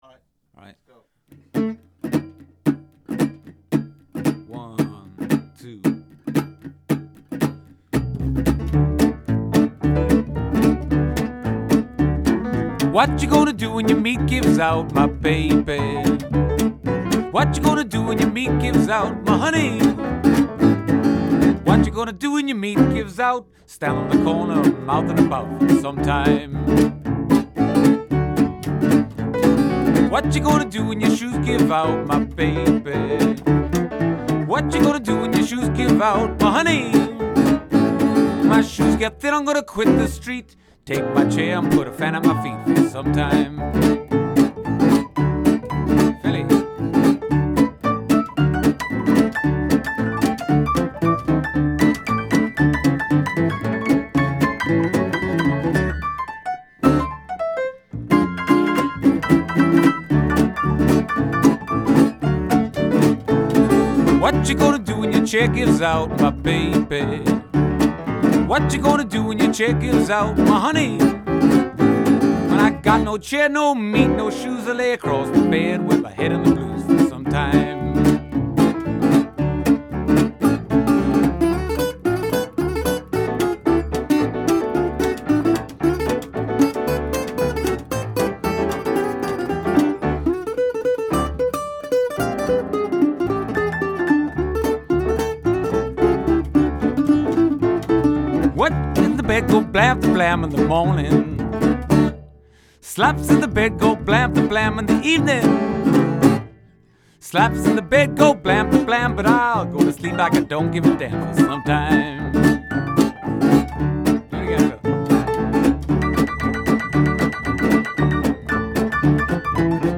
guitar and piano, I sat in on Double bass.